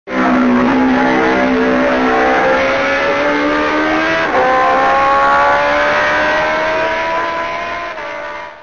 Klicka för att höra Brutale flyga förbi...
brutale_flyby.mp3